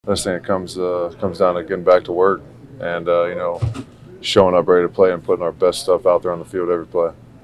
In the locker room yesterday, the players were at a loss to explain how bad they were against the Bills.  Rookie linebacker Jack Sawyer says the only answer he sees is hard work.